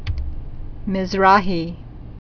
(mĭz-rähē)